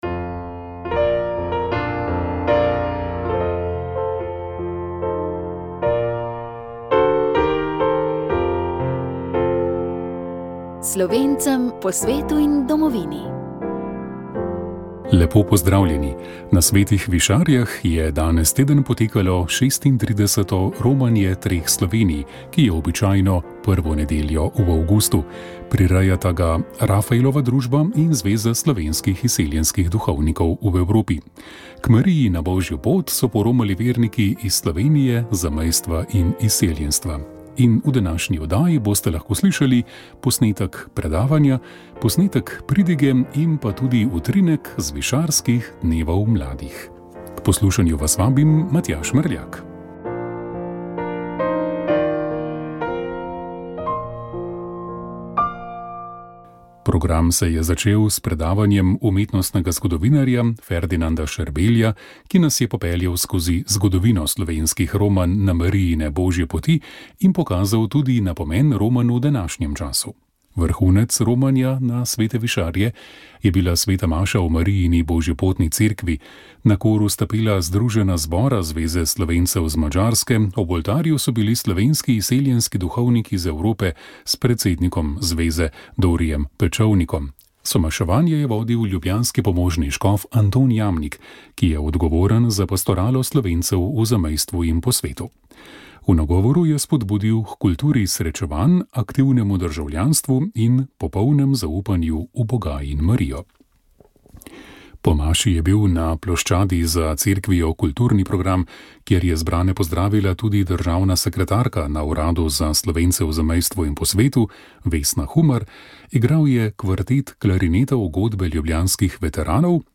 Na Svetih Višarjah je potekalo tradicionalno romanje rojakov iz Slovenije, zamejstva in izseljenstva, ki ga tradicionalno na prvo nedeljo v avgustu prirejata Rafaelova družba in Zveza slovenskih izseljenskih duhovnikov v Evropi.
predavanje
pridigo škofa Antona Jamnika